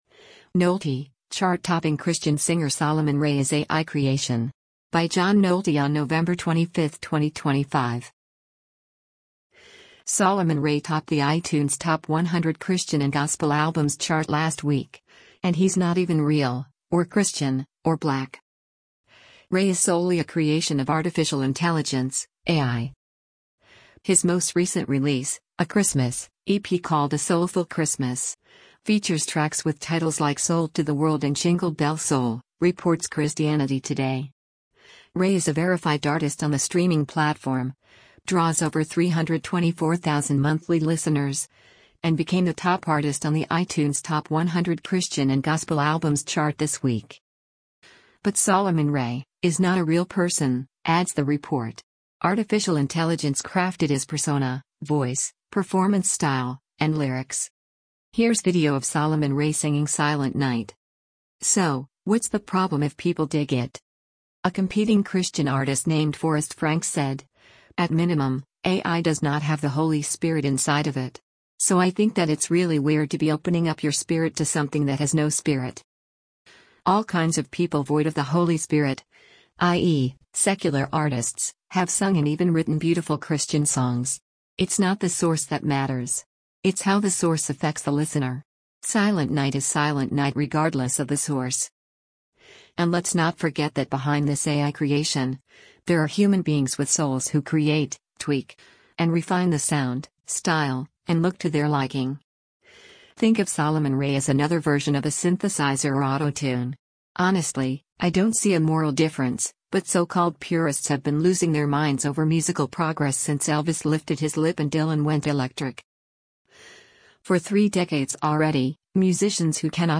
Ray is solely a creation of Artificial Intelligence (AI).
Here’s video of Solomon Ray singing “Silent Night.”